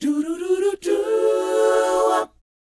DOWOP B AD.wav